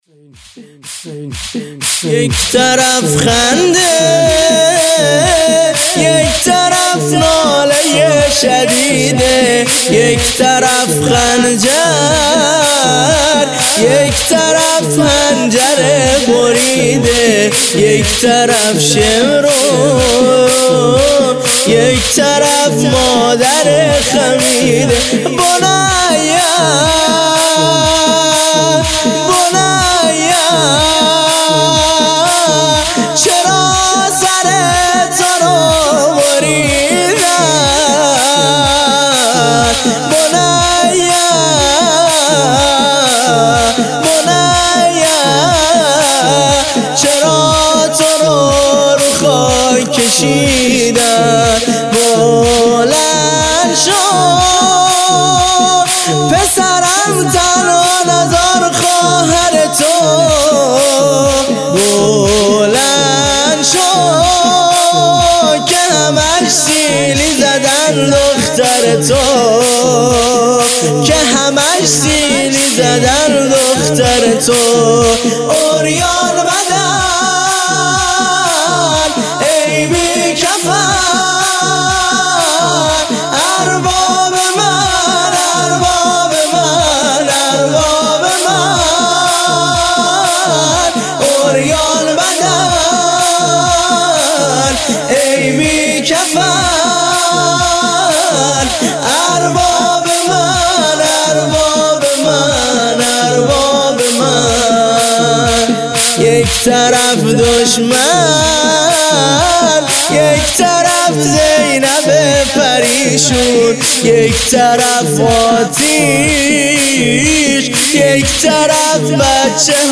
نوحه شور یک طرف خنده یکطرف ناله شدیده
نوحه محرم